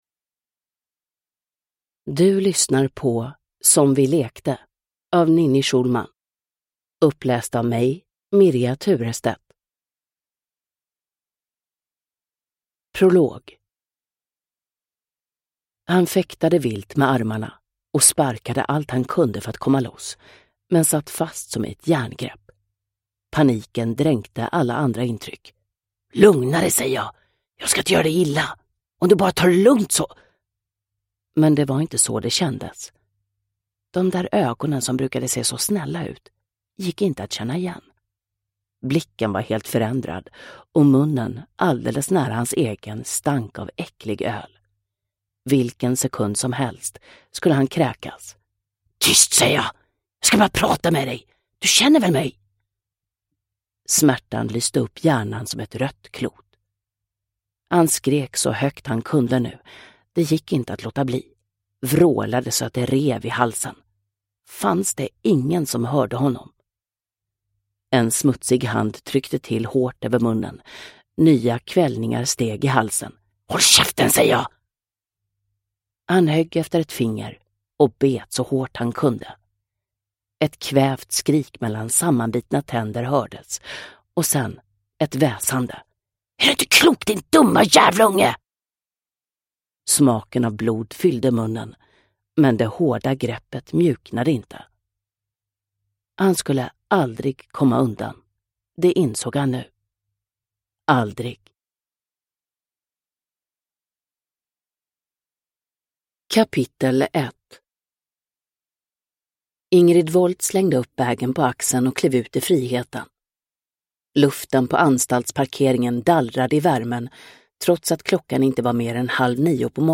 Som vi lekte – Ljudbok – Laddas ner